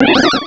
Cri de Babimanta dans Pokémon Diamant et Perle.
Cri_0458_DP.ogg